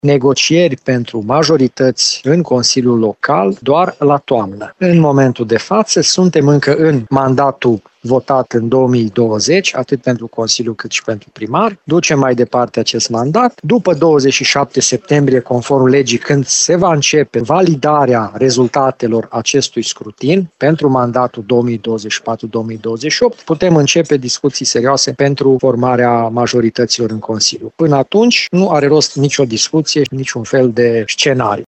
Primarul reales Aradului, Călin Bibarţ spune că nu este de acord cu purtarea acestor negocieri înainte de luna octombrie.